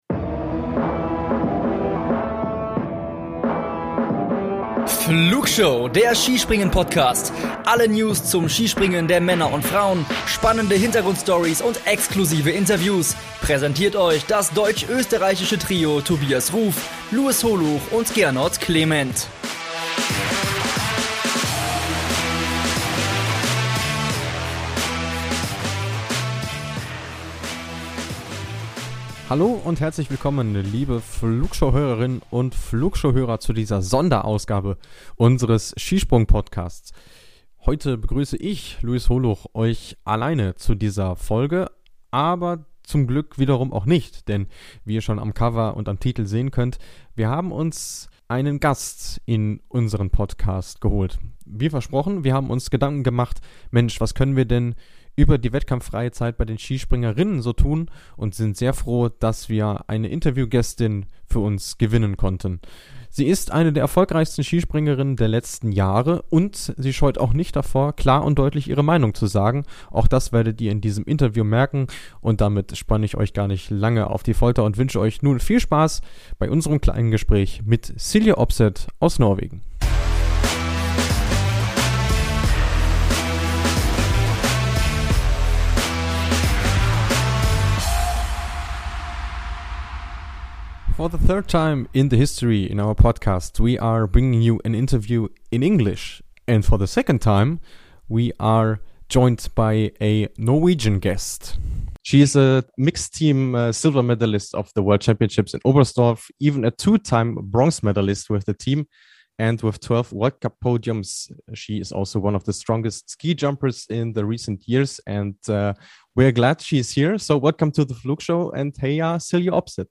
In diesem exklusiven Interview berichtet Silje, wie die Saison bis zur dreiwöchigen Pause für sie verlief und wie sehr sie sich auf die anstehenden Highlights freut.